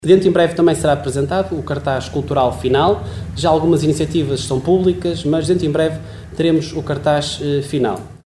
Excertos da reunião de Câmara realizada ontem à tarde no edifício dos Paços do Concelho.